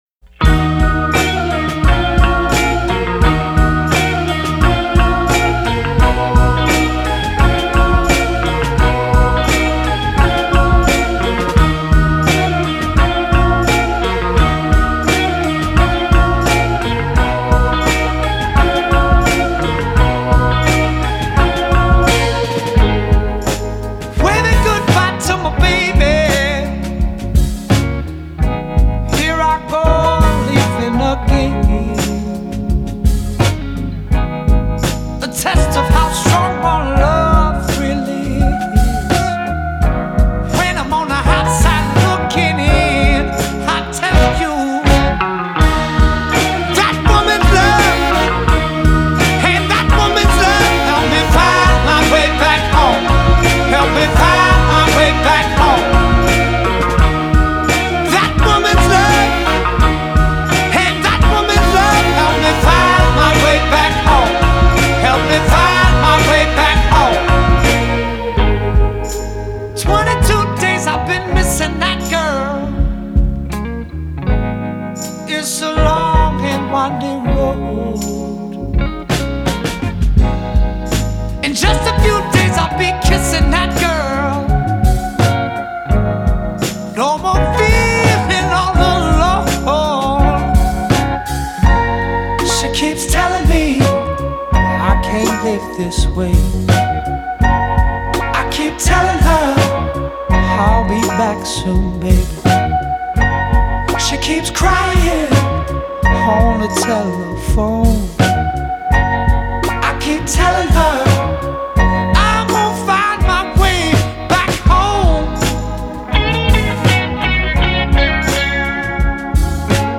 Style: Funk, Soul, Psychedelic